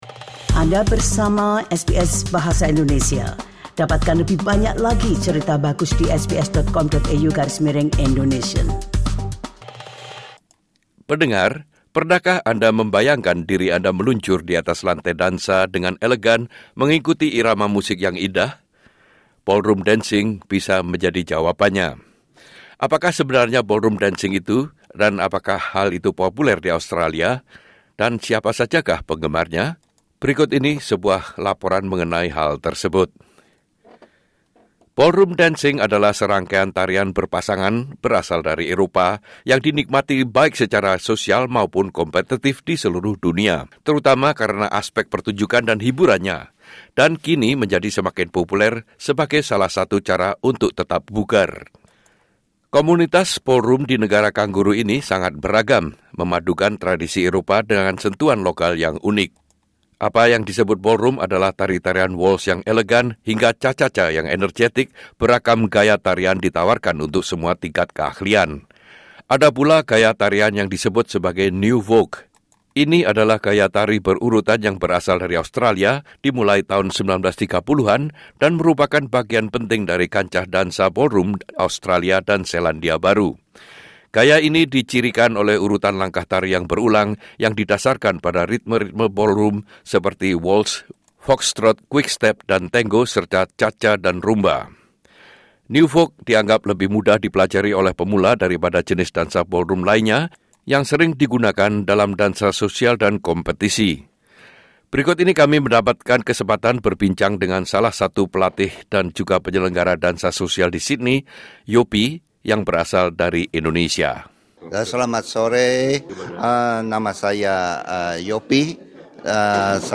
SBS Bahasa Indonesia